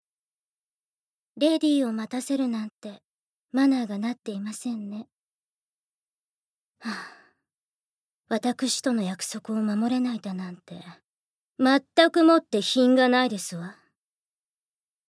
ボイスサンプル
セリフ６